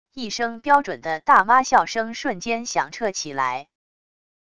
一声标准的大妈笑声瞬间响彻起来wav音频